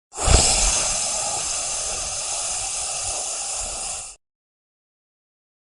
Jetpack Gas On
Jetpack Gas On is a free sfx sound effect available for download in MP3 format.
yt_u2UmLphAw-U_jetpack_gas_on.mp3